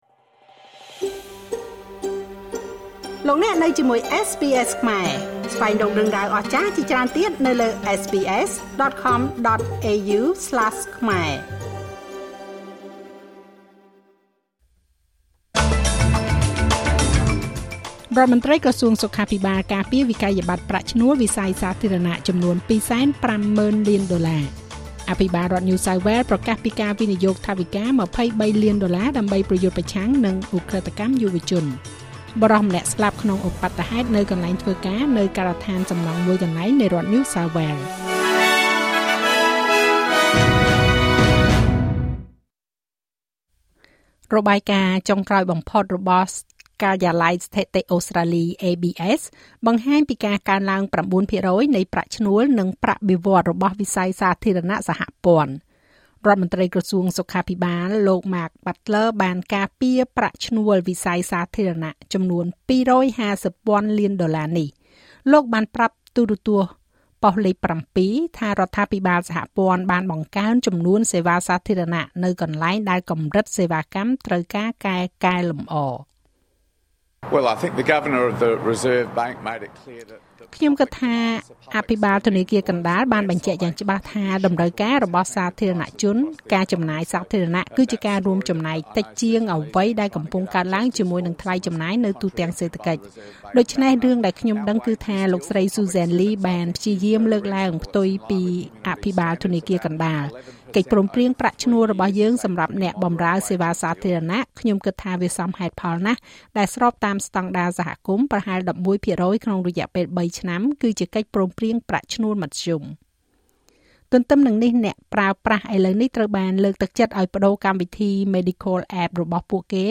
នាទីព័ត៌មានរបស់SBSខ្មែរសម្រាប់ថ្ងៃសុក្រ ទី៧ ខែវិច្ឆិកា ឆ្នាំ២០២៥